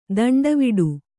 ♪ danḍaviḍu